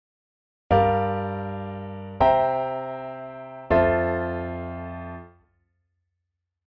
If you create the 2-5-1 progression from these seventh chords, it looks like this, with the sevenths marked in blue:
G minor 7 piano chord
C7 piano chord chart
F major 7 piano chord
2-5-1-F-jazz-piano.mp3